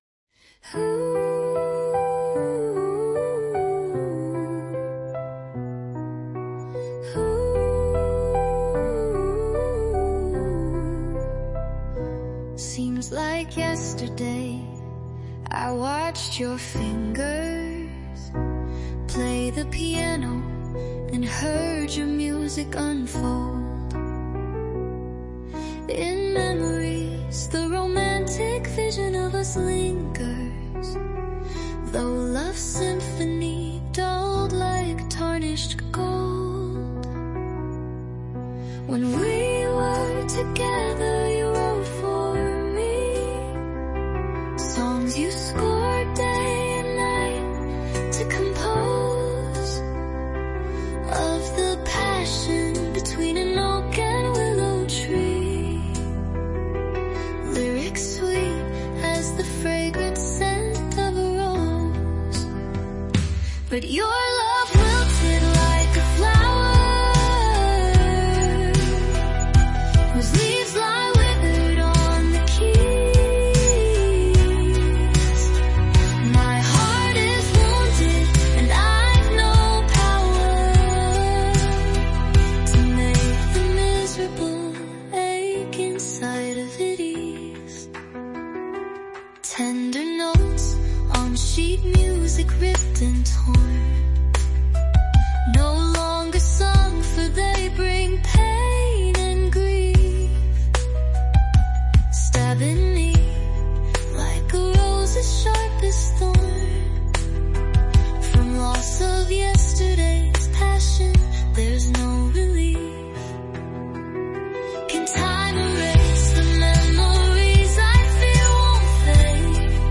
Beautiful music and voice.